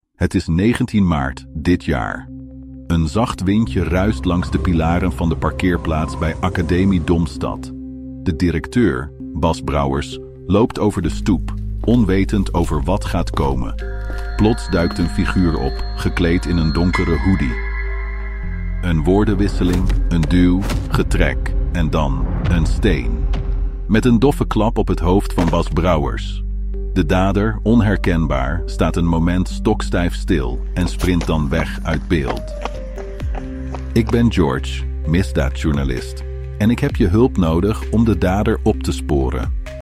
De studenten krijgen iedere sessie tips van een AI-gegenereerde misdaadjournalist, George, en leren gaandeweg onder meer vibe coden (programmeren met behulp van generatieve AI), scrapen (geautomatiseerd online informatie verzamelen), geautomatiseerde data-analyses doen, beelden verifiëren met AI en OSINT-tools en storyboards maken met generatieve AI.